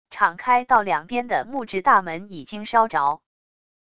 敞开到两边的木制大门已经烧着wav音频